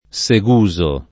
[ S e g2@ o ]